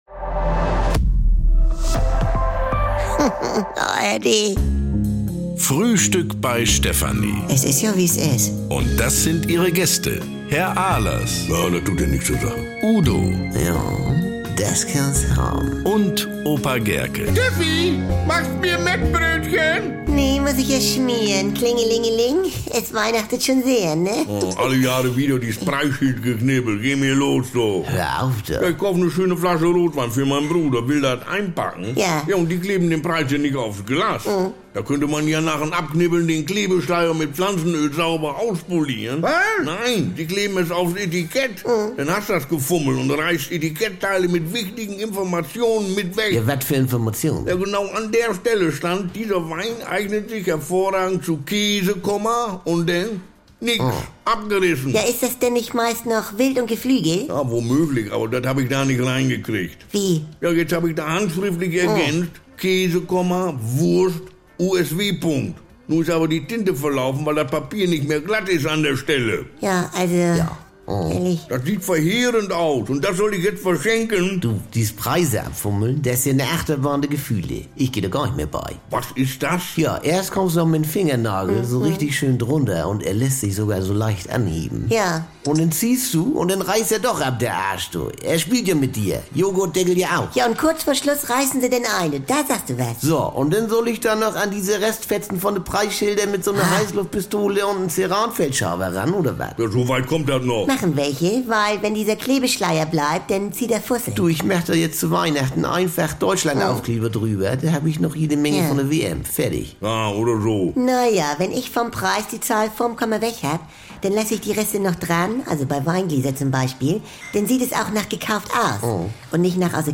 NDR 2 Komödie Frühstück Bei Stefanie
Garantiert norddeutsch mit trockenen Kommentaren, deftigem Humor und leckeren Missverständnissen.